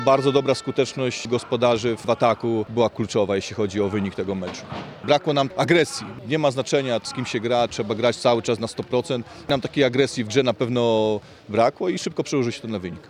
powiedział tuż po zakończeniu spotkania